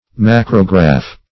Search Result for " macrograph" : The Collaborative International Dictionary of English v.0.48: Macrograph \Mac"ro*graph\, n. [Macro- + -graph.]